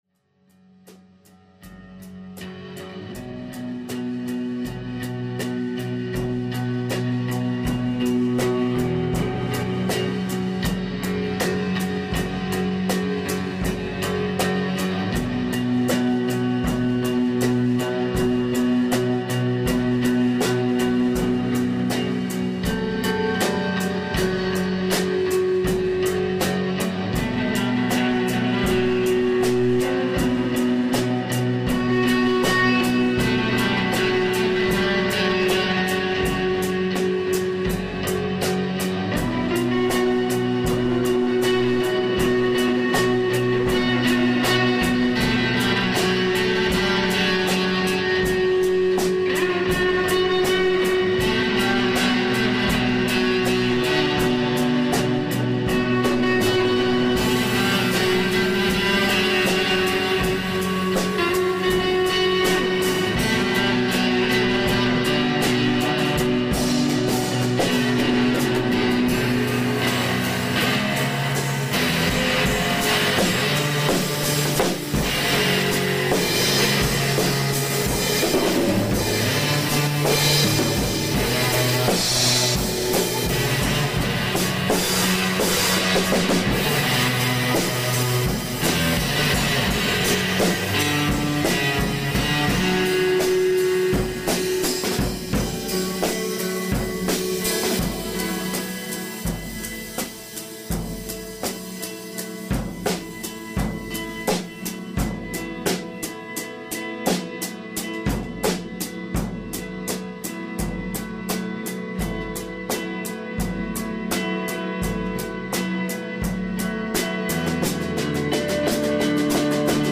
brooding improv 2-17-22